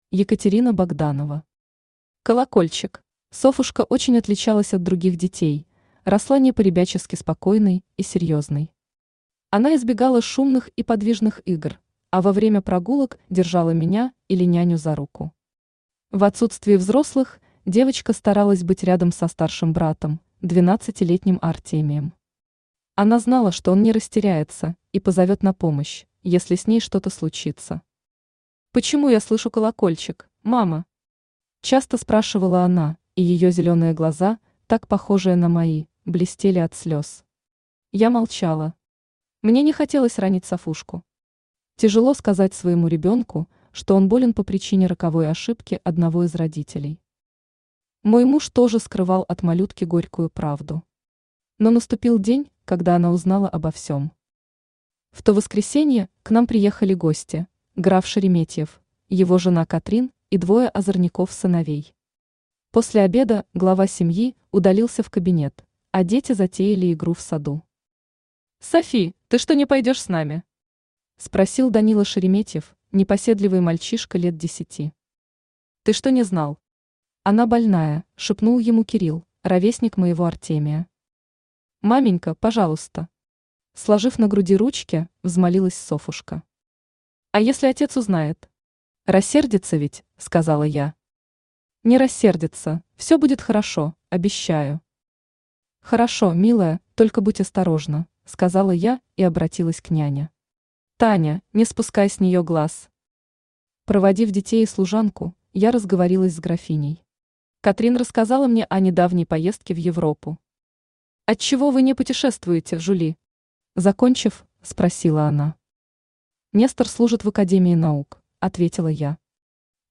Aудиокнига Колокольчик Автор Екатерина Андреевна Богданова Читает аудиокнигу Авточтец ЛитРес.